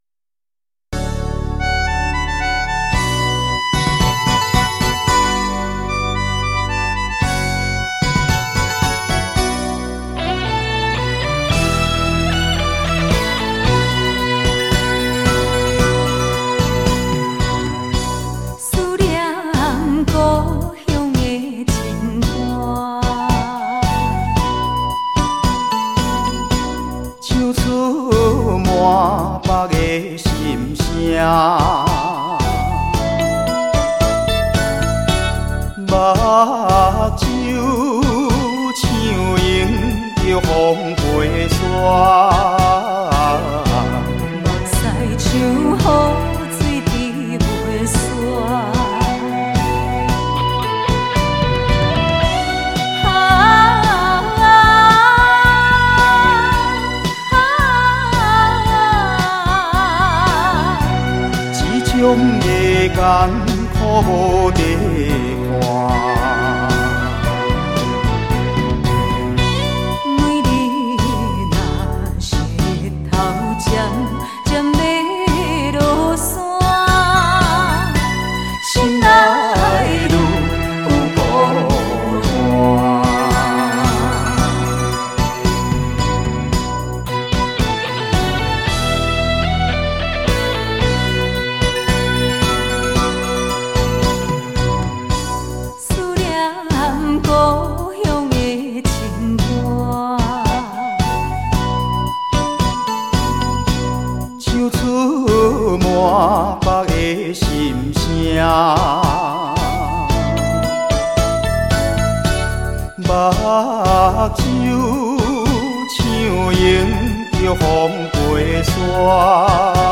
情歌对唱
台语KTV点唱
重新编曲 演唱